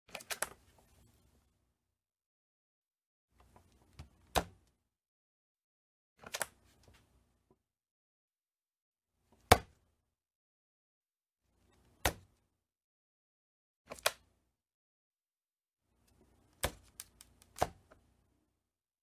Tổng hợp tiếng kéo Đóng, Mở màn hình Laptop
Thể loại: Tiếng đồ công nghệ
Description: Hiệu ứng âm thanh đóng mở nắp máy tính xách tay, tiếng mở và gập đóng màn hình máy tính xách tay, máy tính Macbook sột soạt, rọc rẹc, Laptop Lid Open & Close, Opening and closing laptop sound effect...
tong-hop-tieng-keo-dong-mo-man-hinh-laptop-www_tiengdong_com.mp3